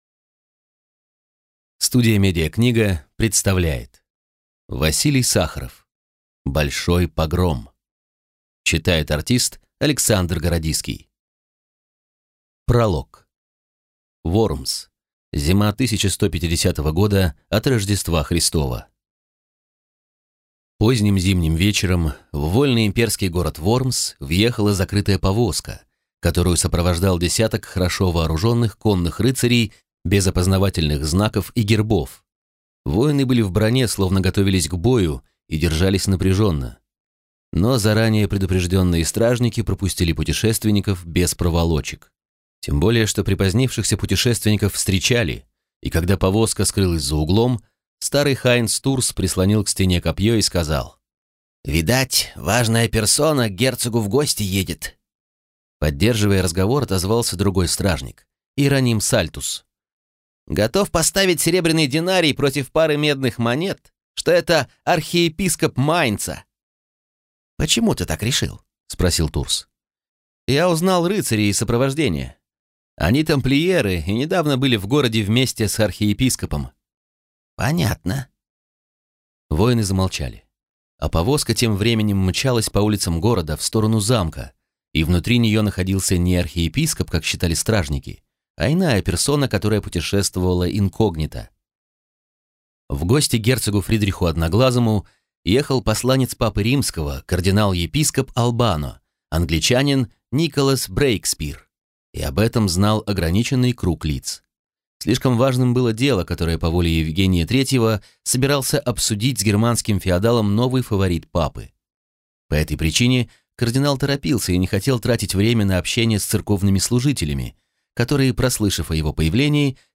Аудиокнига Большой погром | Библиотека аудиокниг